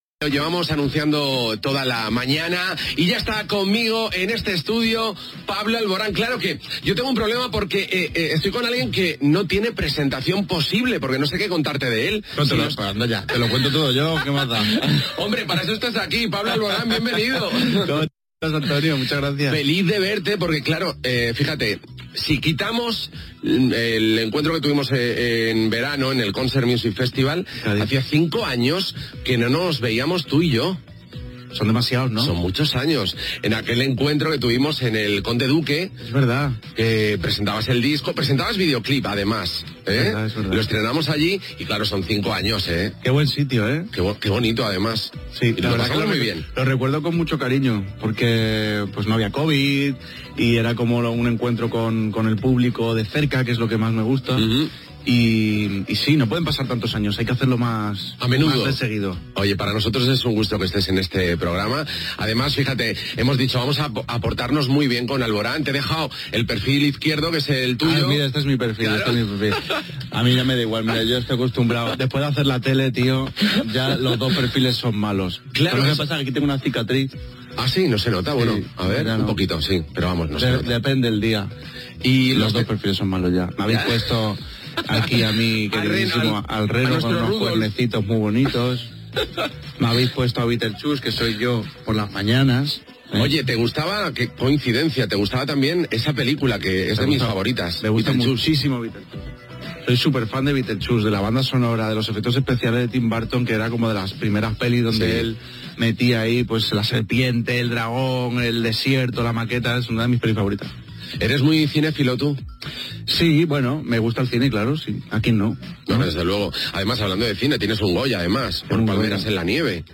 Fragment d'una entrevista al cantant Pablo Alborán. Conversa telefònica amb Irene Villa, víctima d'un atemptat d'ETA.